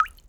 pgs/Assets/Audio/Water/water_drop_drip_single_20.wav
water_drop_drip_single_20.wav